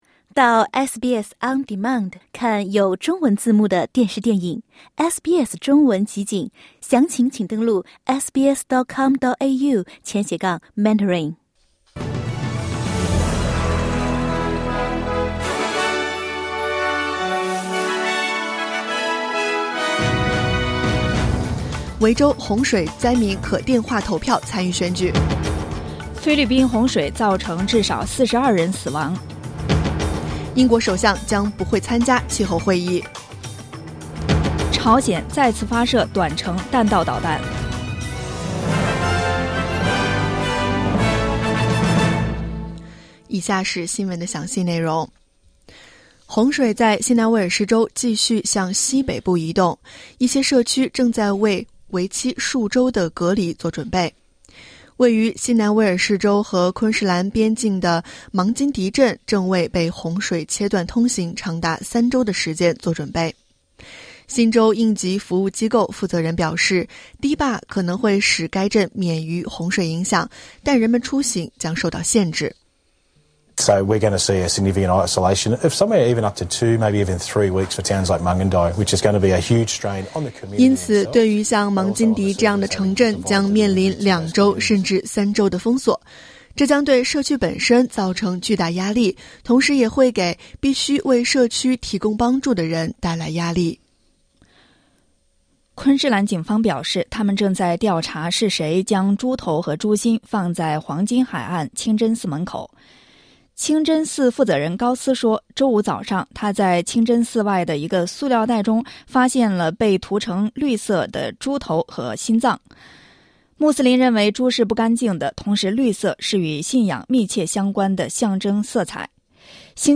SBS早新闻（10月29日）